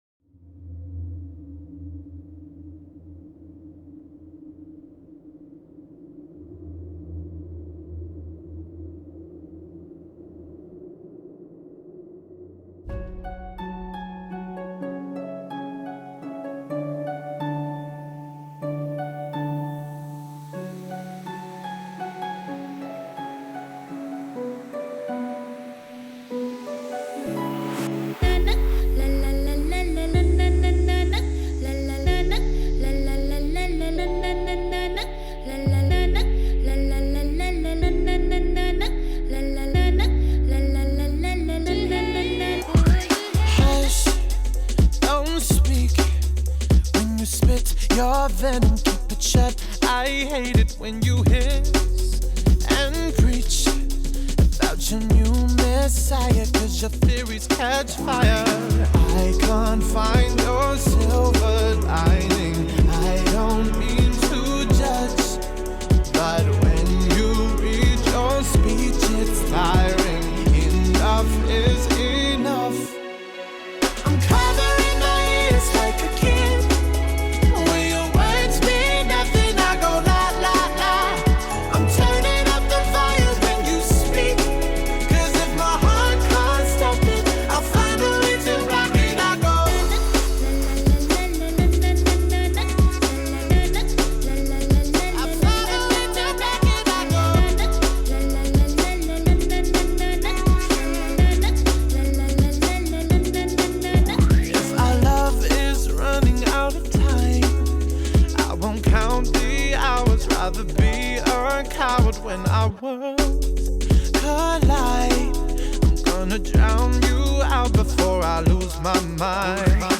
Жанр: Зарубежная музыка